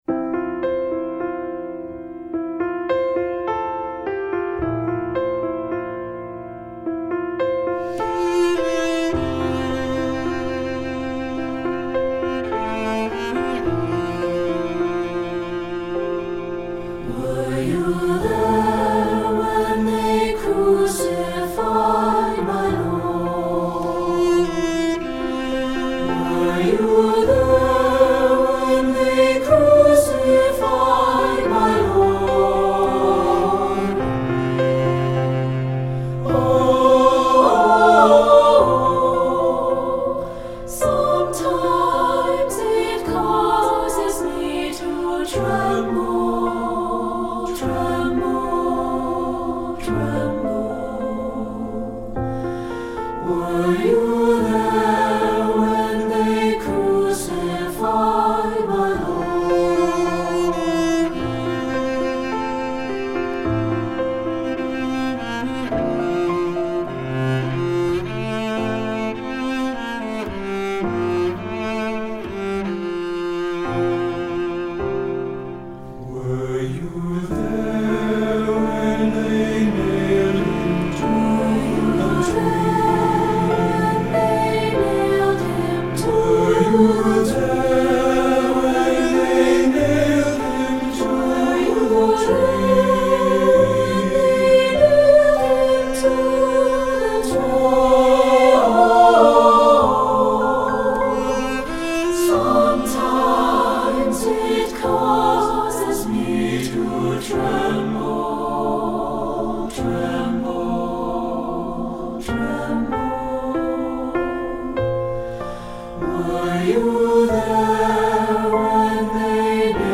Composer: African American Spiritual
Voicing: SATB and Piano